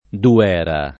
Dovera [ dov $ ra ] top.